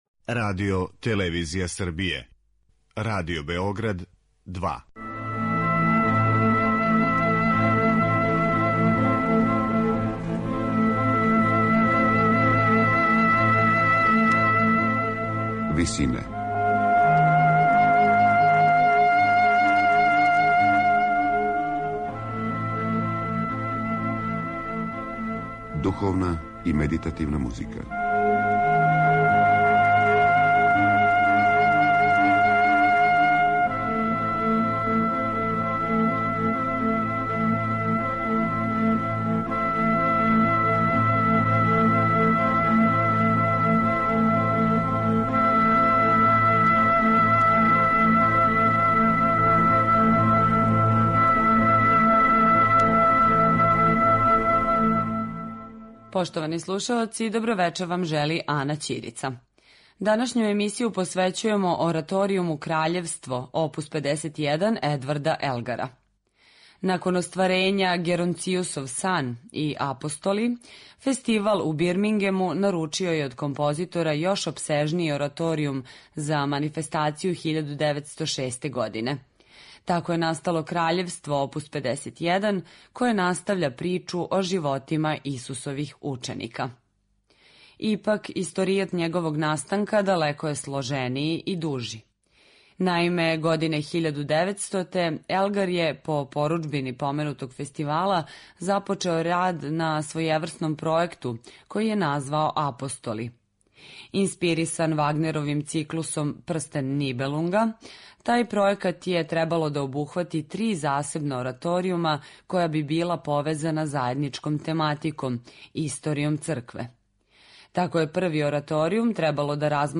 Компоновано за двоструки хор, велики, позноромантичарски састав оркестра и четворо солиста, Краљевство је било замишљено као централни део грандиозног и недовршеног Елгаровог пројекта - ораторијумске трилогије Апостоли.